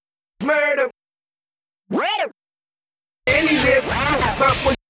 For a smooth vinyl “scratching” effect try this code in Nyquist Prompt, (tick “version 3 syntax” box)
It seems to me like it has a start pitch higher than the original pitch, then goes lower, then repeats, if the depth is higher than 1.